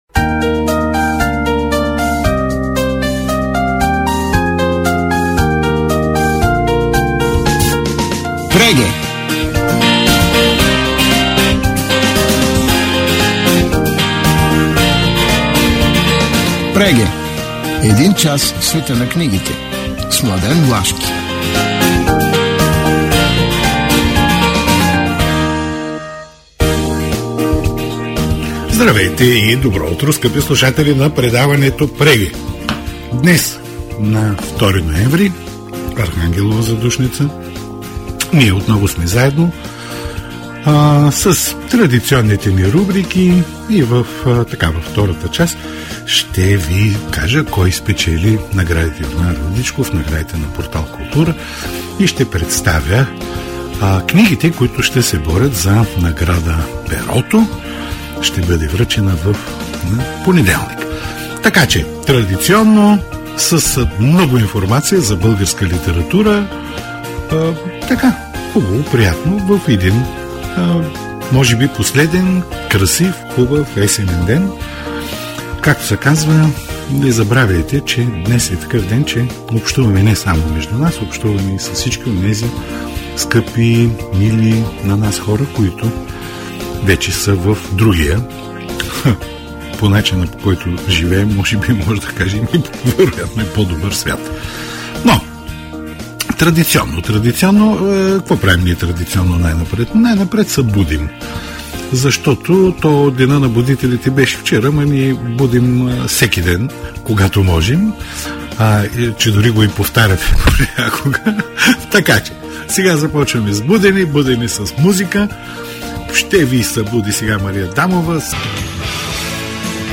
В Преге – предаването за книги на Радио Пловдив, на 2 ноември 2024 бяха представени следните заглавия: